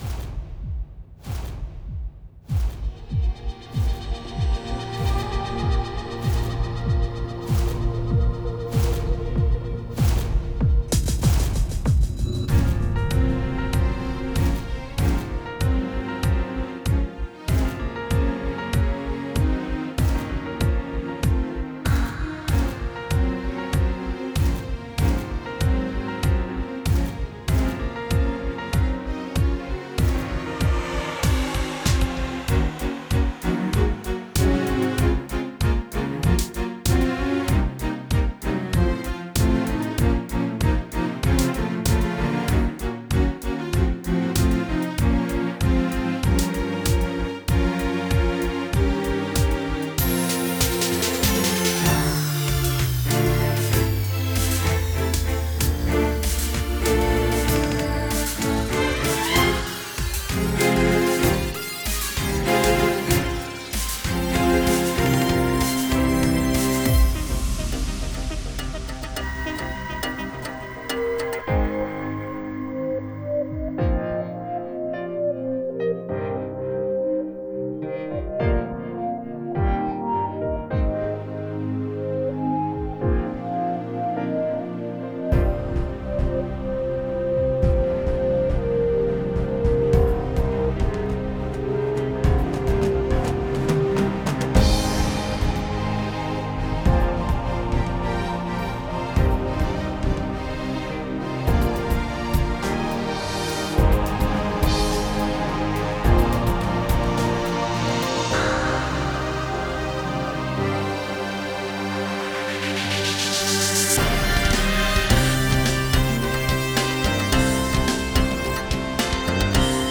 MR